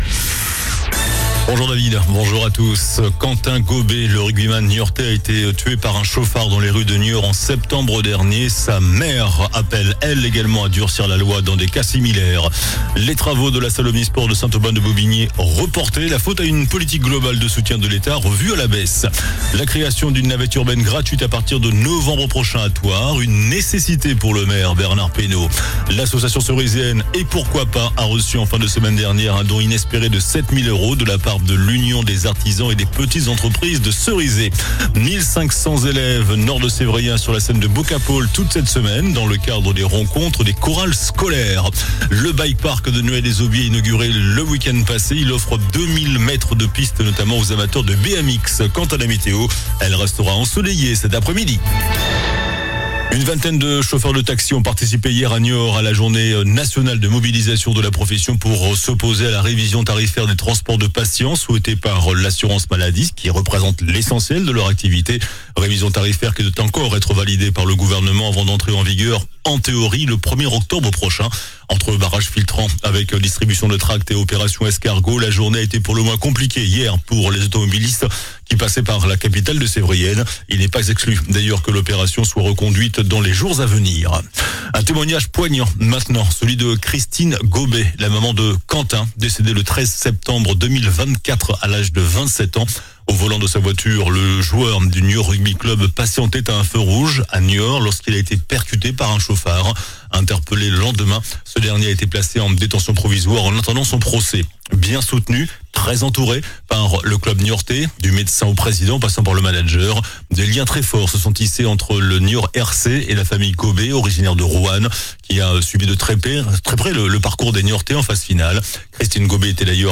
JOURNAL DU MARDI 20 MAI ( MIDI )